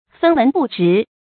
分文不值 fēn wén bù zhí 成语解释 形容毫无价值 成语出处 宋 释普济《五灯会元 漳州保福院从展禅师》：“有人赞叹此事如虎戴角；有人轻毁此事 分文不值 。”